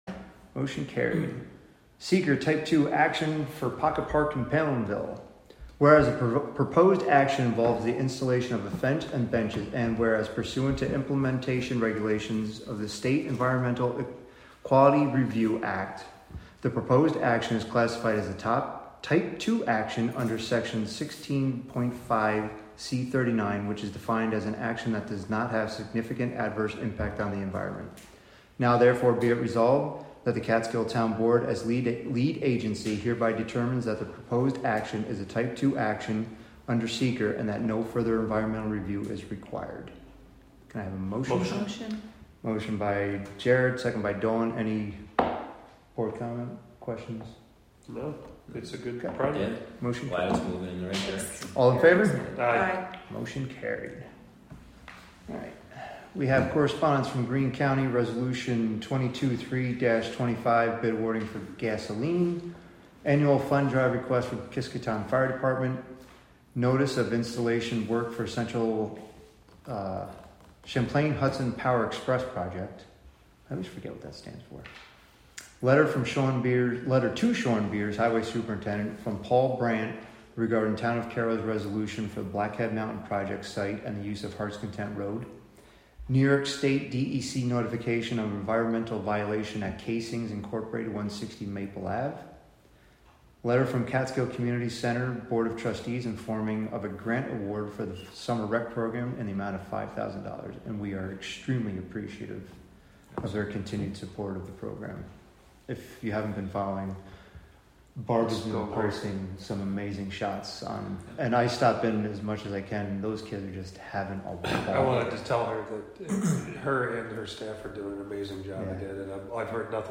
Live from the Town of Catskill: August 5, 2025 Catskill Town Board Monthly Meeting (Audio)